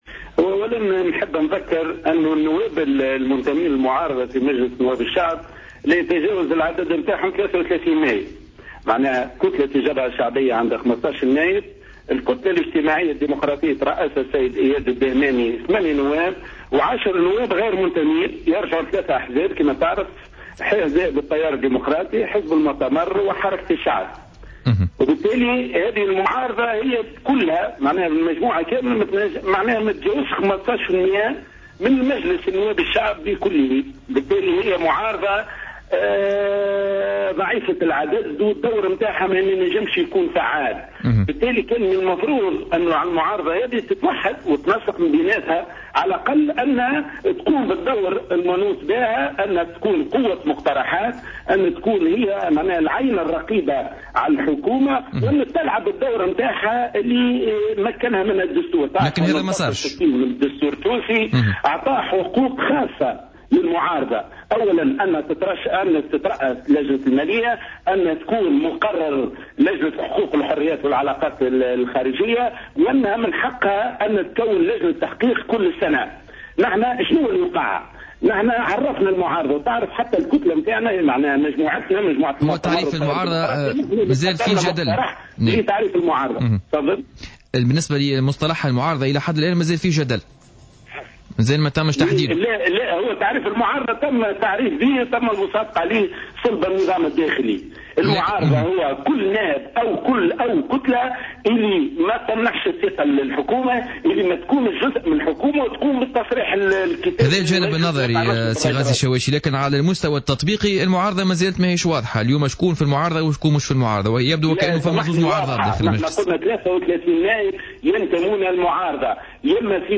اتهم نائب مجلس الشعب عن التيار الديمقراطي غازي الشواشي خلال مداخلة له اليوم الاثنين في برنامج "بوليتيكا" الجبهة الشعبية بتعطيل أعمال مجلس الشعب.